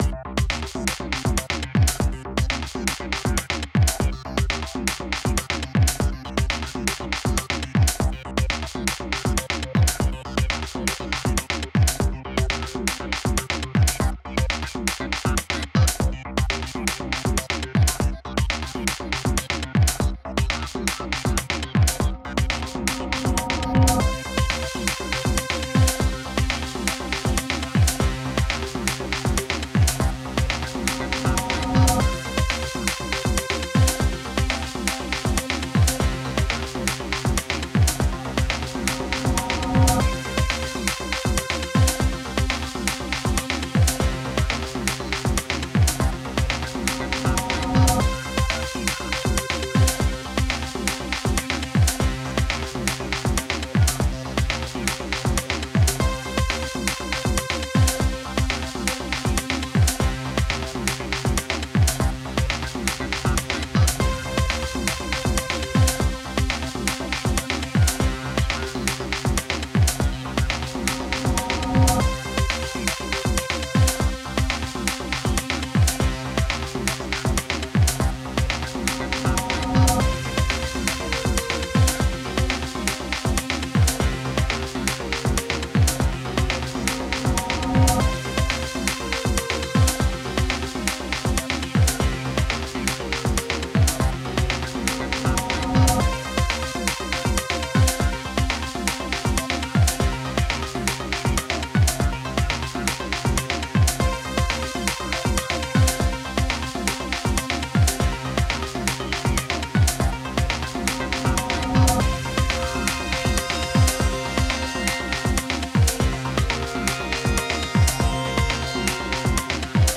Something something glitchy hybrid chiptune PART ONE